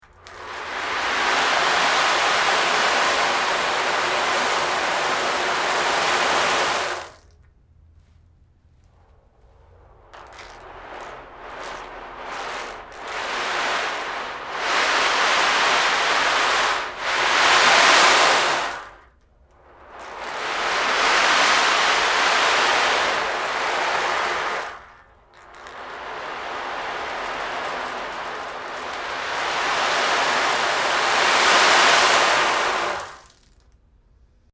Schoeps Vintage CMT 341 Microphone: CMT 3 body with MK41 hypercardioid capsule
Rain Drum |
SIGNAL CHAIN: Schoeps CMT 341 / Presonus ADL 600 / Rosetta 200 / Logic.
Rain.mp3